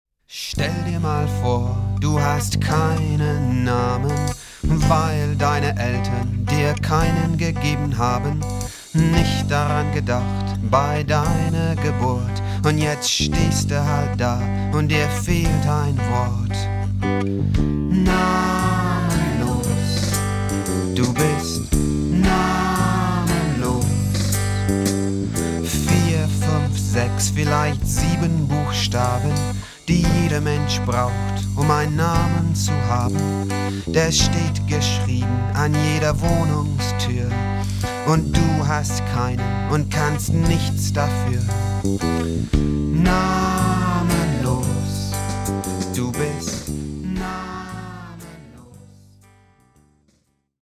Lied-Geschichten zum Zuhören und Mitsingen.